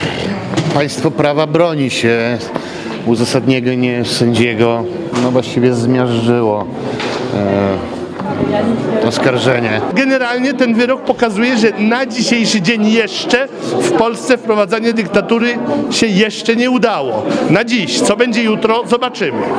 Zebrana na sali rozpraw publiczność przyjęła wyrok brawami. Po opuszczeniu sali zebrani krzyczeli – „wolny sędzia!”.